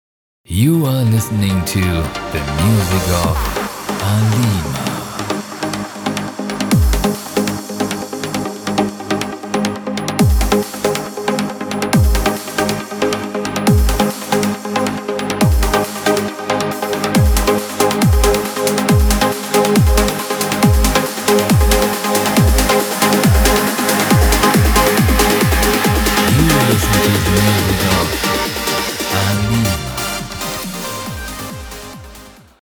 Extended Mix